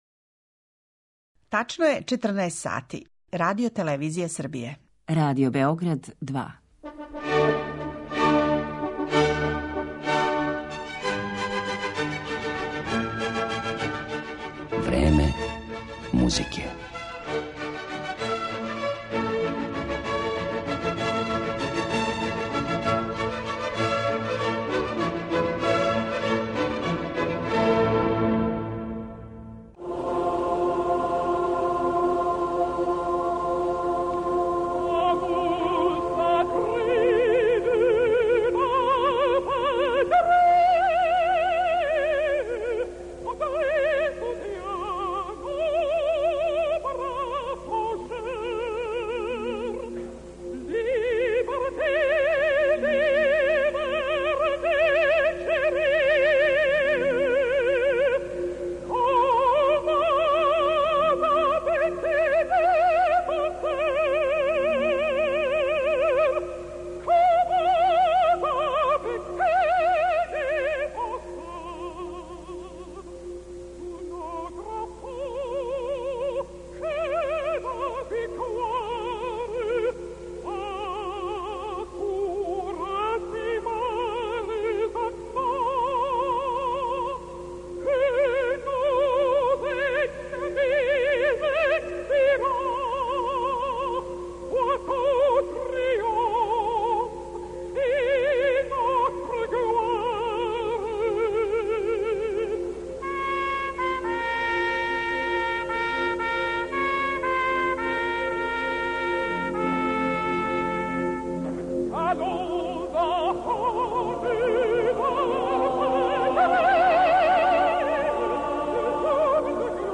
У емисији ВРЕМЕ МУЗИКЕ слушаћете причу о чувеној „Марсељези"; о томе како је настала, како је постала национална химна Француске и како су је, у својим делима, користили многи композитори уметничке па и комерцијалне музике. Њен аутор је Клод Жозеф Руже де Лил, а чућете и музику коју су на основу „Марсељезе" компоновали, између осталих и Клод Бењин Балбастр, Роберт Шуман, Петар Иљич Чајковски, Ђоакино Росини, Клод Дебиси, Ерик Сати и Игор Стравински.